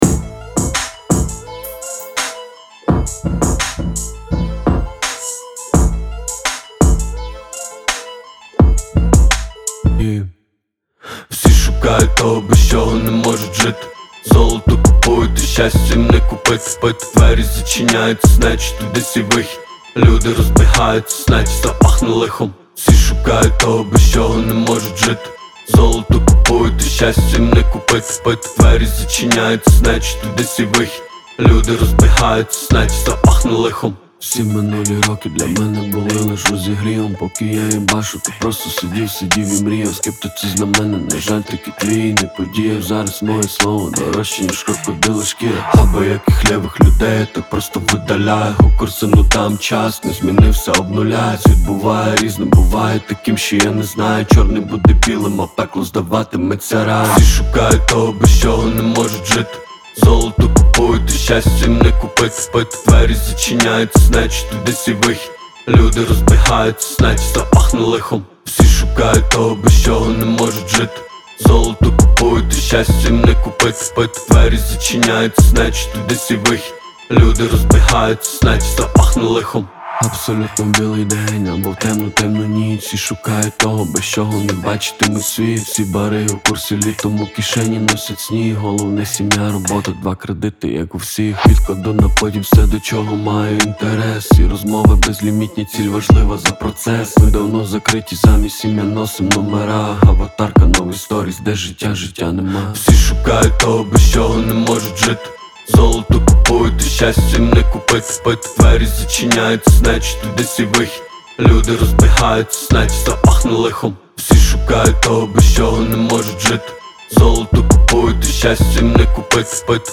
• Жанр:Реп